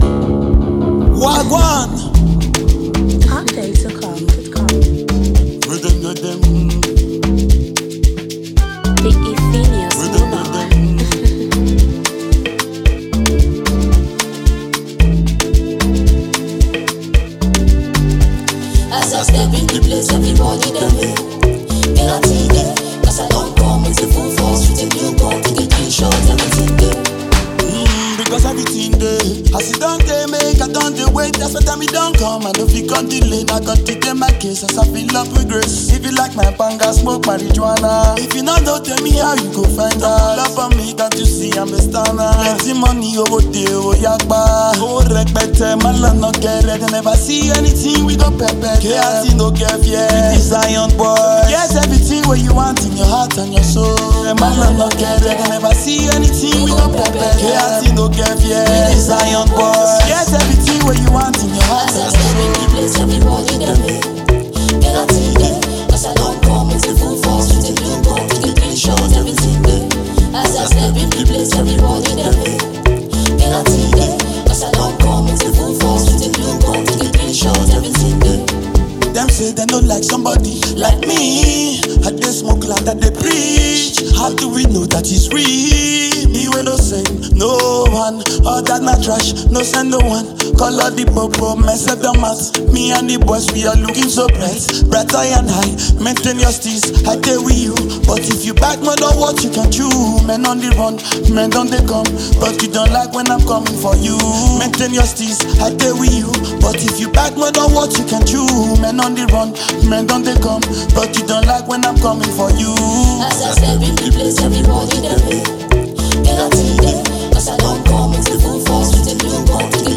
afro beat